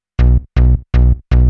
hous-tec / 160bpm / bass